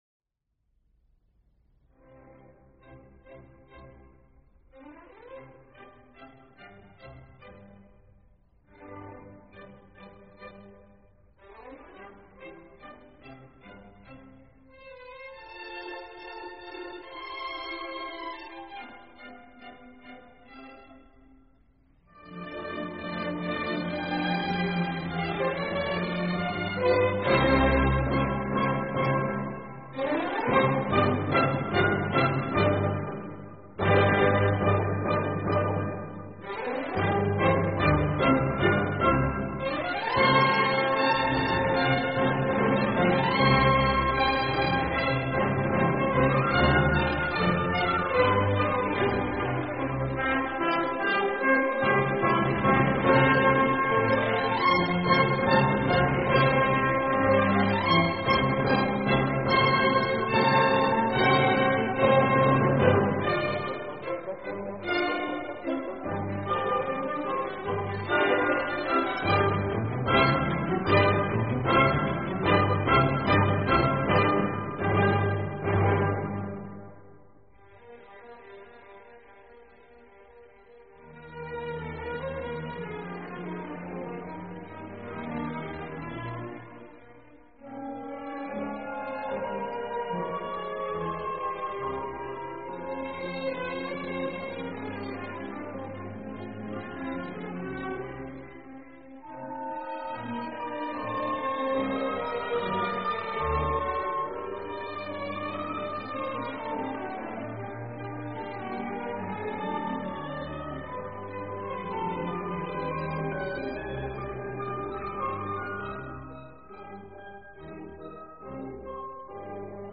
Allegro assai（32K/MP3） 感谢原发者！